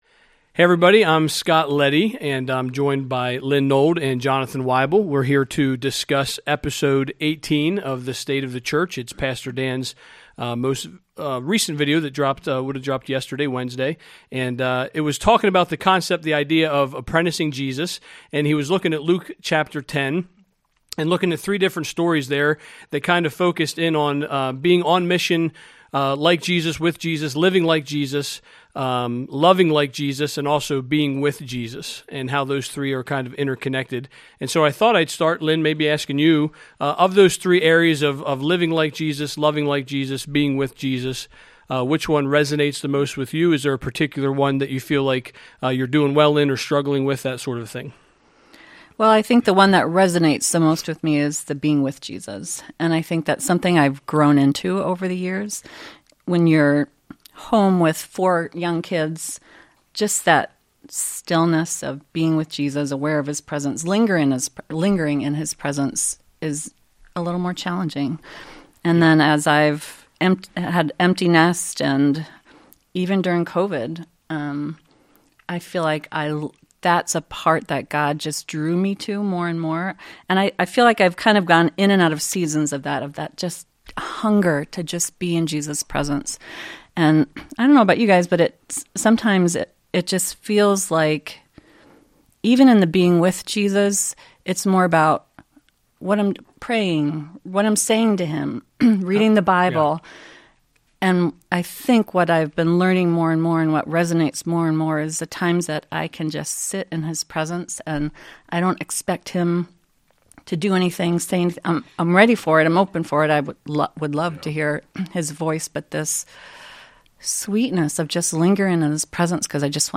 Ep 18. State of the Church | A Conversation about Apprenticing Jesus | Calvary Portal | Calvary Portal